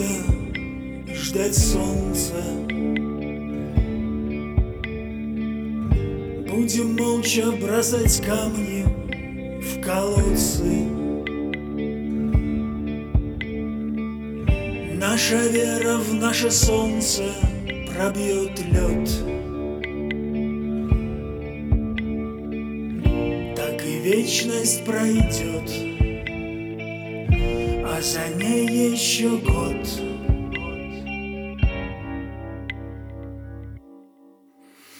Жанр: Музыка из фильмов / Саундтреки / Русские